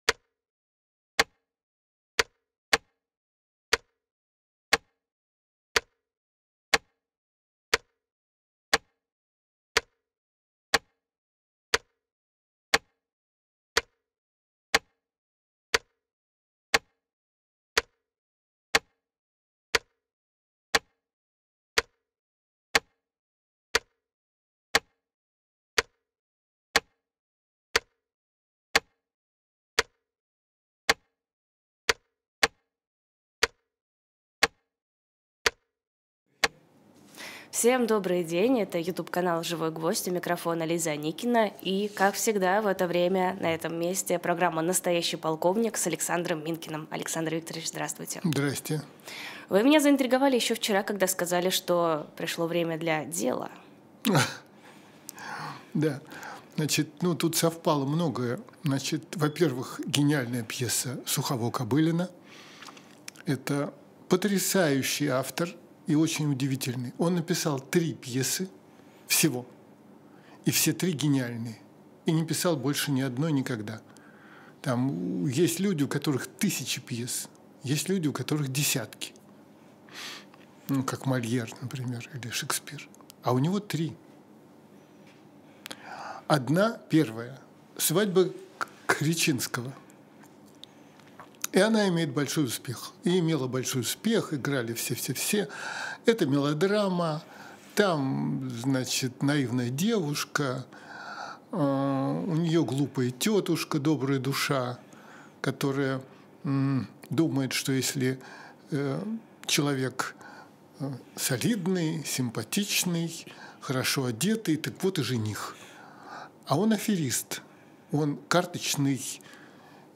Александр Минкин журналист